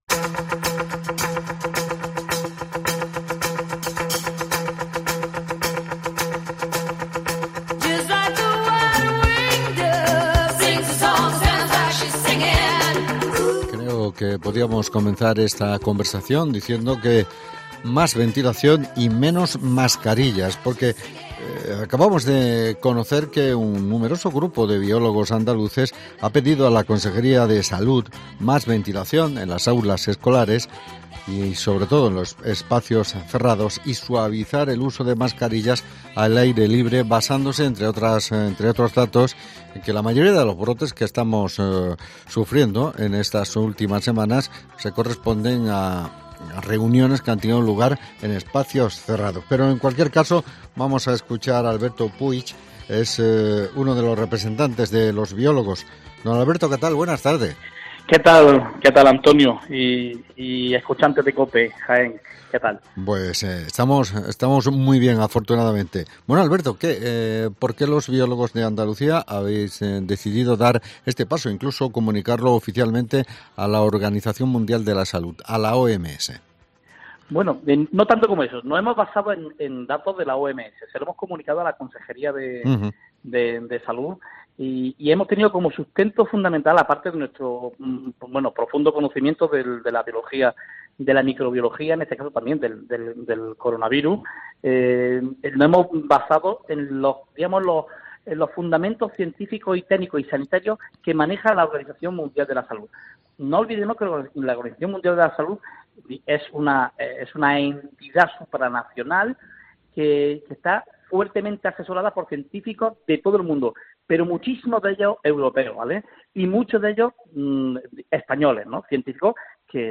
Entrevistamos al biólogo